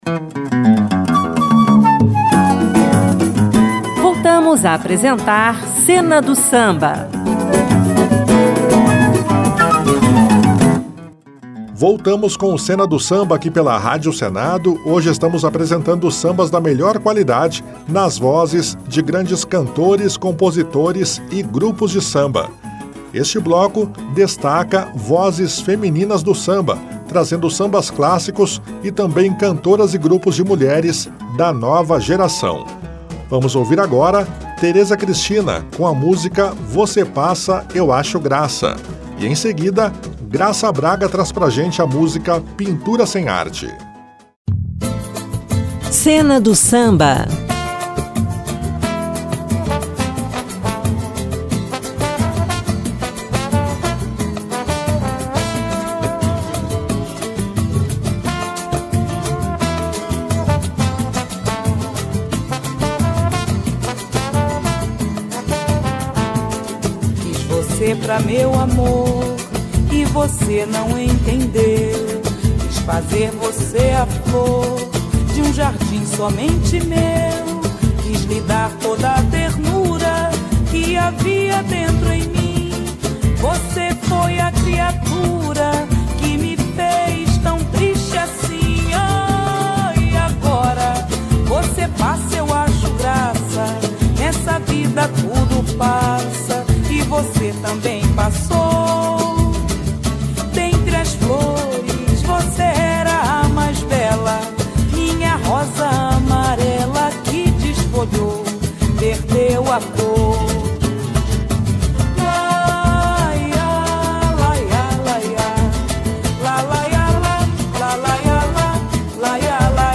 O Cena do Samba apresenta uma seleção especial de sambas clássicos, lançamentos e um bloco destinado a vozes femininas do samba.